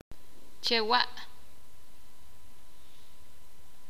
[ʧ͡ak.’ne:m xla:ɓ.’ʃe:l̥] verbo Call the spirit